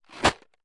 Tag: 140 bpm Trap Loops Bells Loops 1.15 MB wav Key : B FL Studio